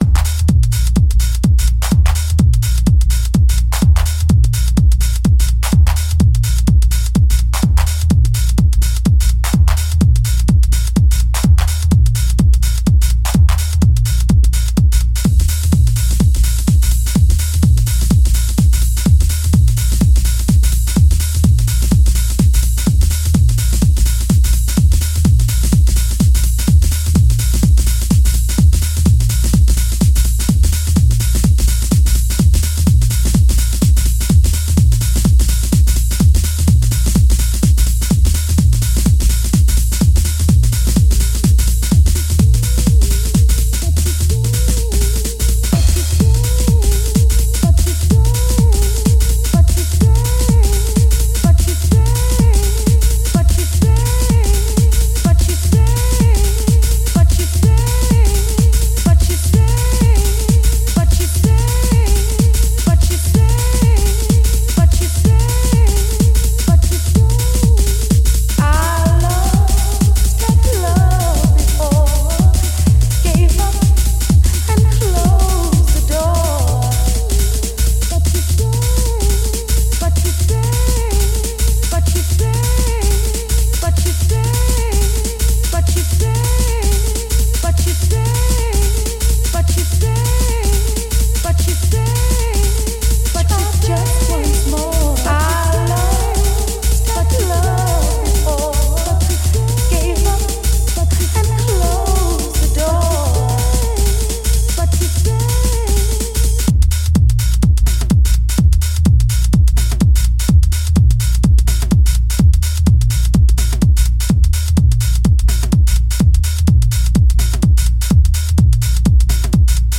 reworking some house classics and underground gems